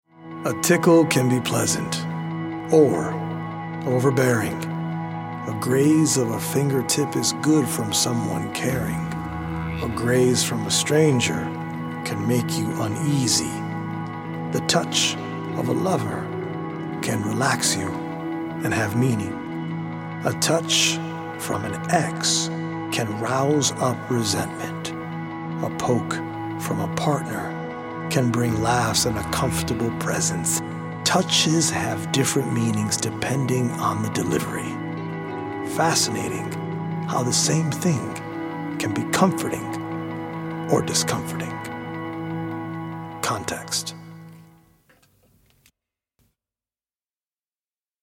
healing Solfeggio frequency music
EDM